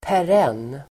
Uttal: [pär'en:]